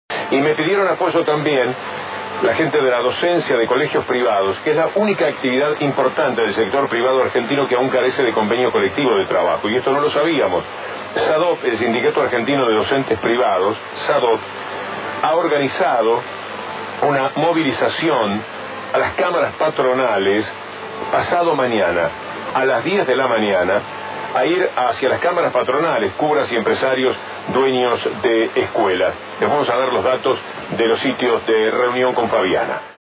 El programa de radio que conduce el periodista Víctor Hugo Morales, "La Mañana" por Continental AM 590, informa sobre la marcha que realizará el Sindicato el jueves 18 de octubre a las Cámaras Patronales.